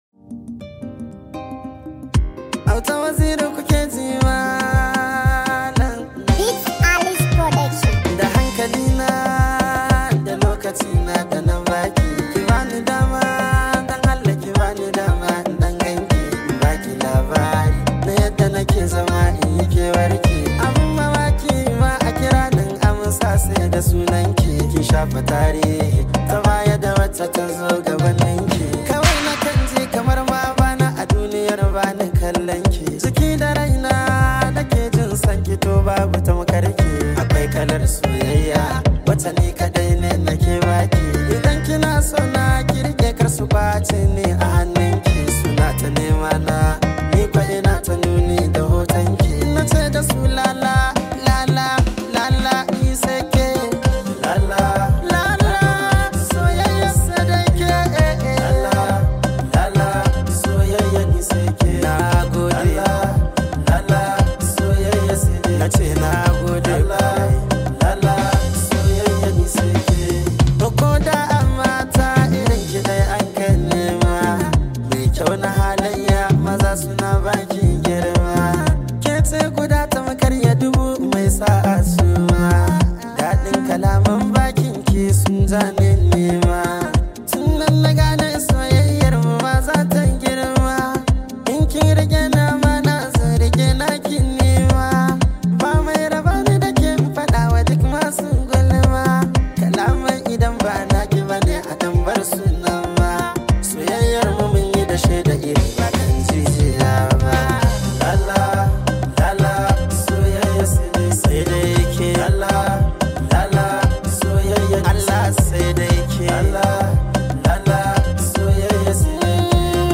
Hausa Singer